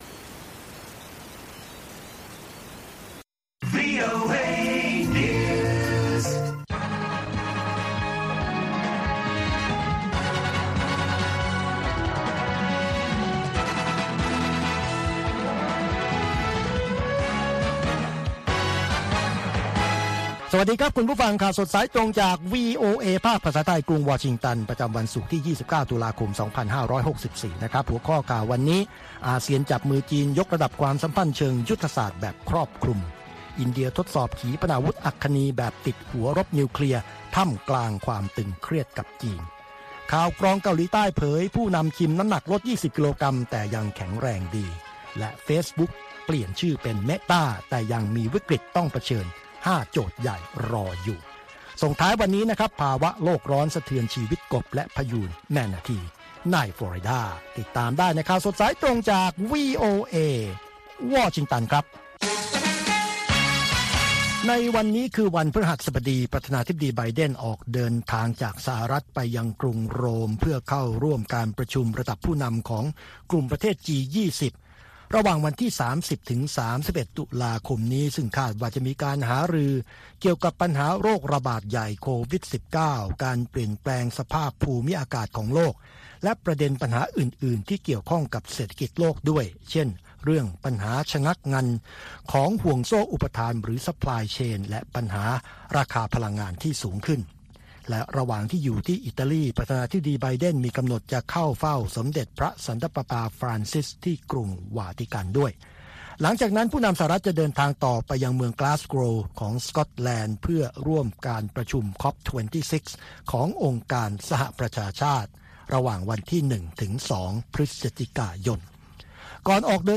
ข่าวสดสายตรงจากวีโอเอ ภาคภาษาไทย คุยข่าวรอบโลกกับวีโอเอไทย ประจำวันศุกร์ที่ 29 ตุลาคม 2564 ตามเวลาประเทศไทย